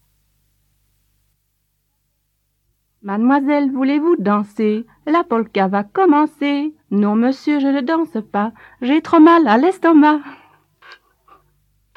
Genre : chant
Type : chanson d'enfants
Interprète(s) : Anonyme (femme)
Support : bande magnétique